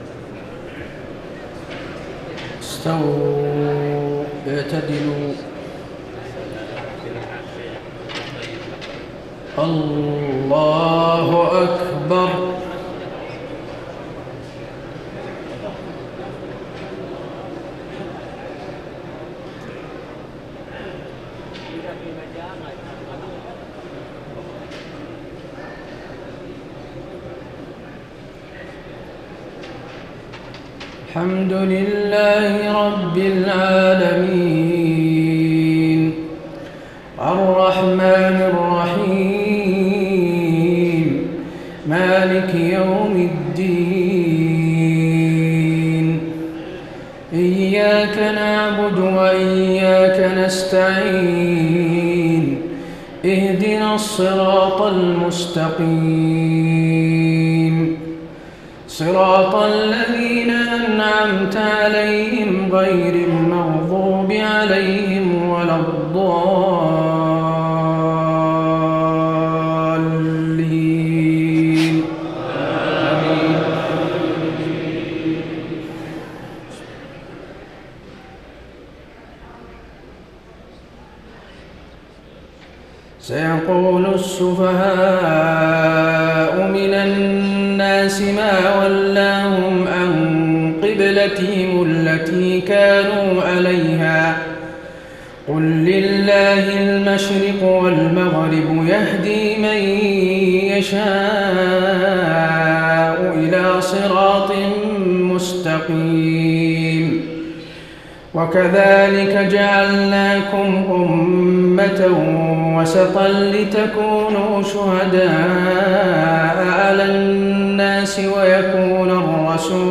تهجد ليلة 22 رمضان 1433هـ من سورة البقرة (142-218) Tahajjud 22 st night Ramadan 1433H from Surah Al-Baqara > تراويح الحرم النبوي عام 1433 🕌 > التراويح - تلاوات الحرمين